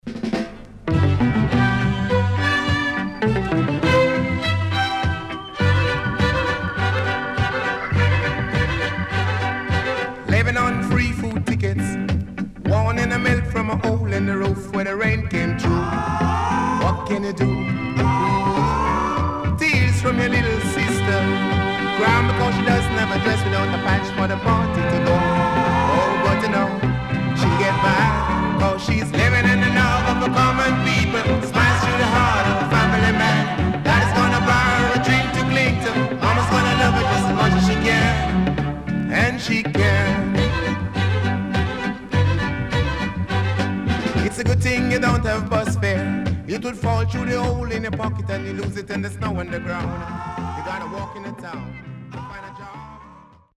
Jazzy Horn Inst